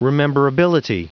Vous êtes ici : Cours d'anglais > Outils | Audio/Vidéo > Lire un mot à haute voix > Lire le mot rememberability
Prononciation du mot : rememberability